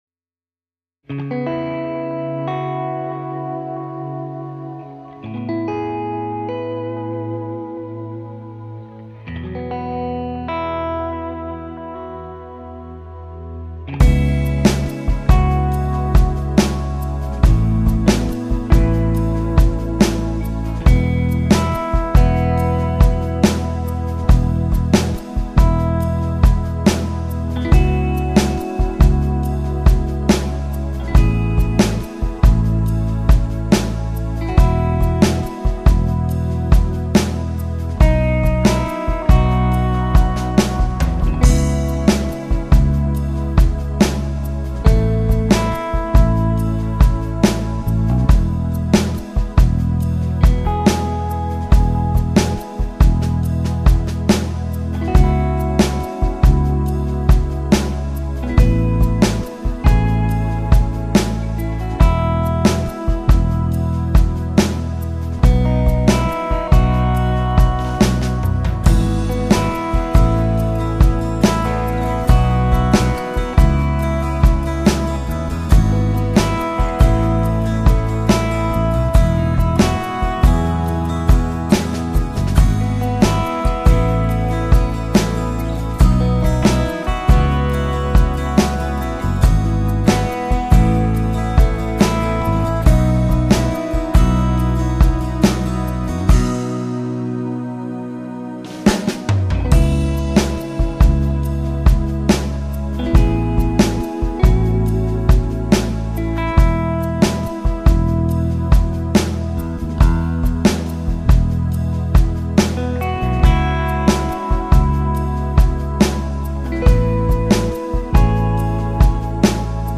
Jam-Track-in-E.mp3